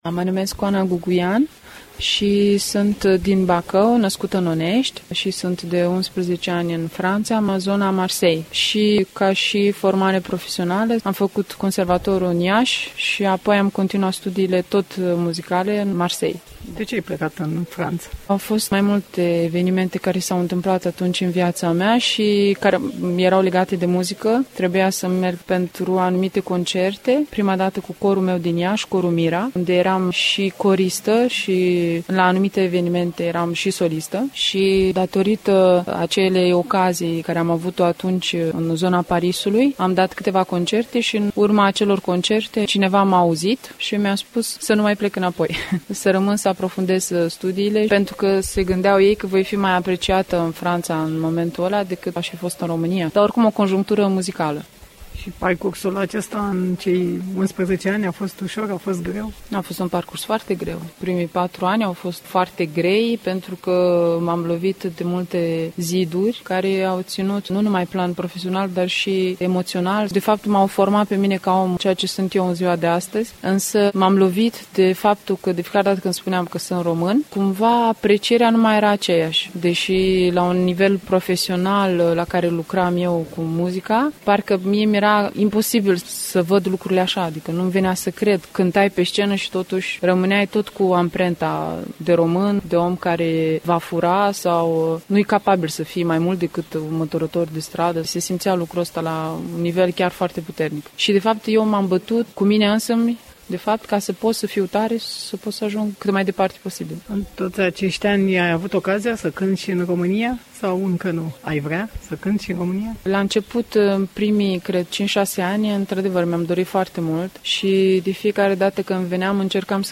Într-un interviu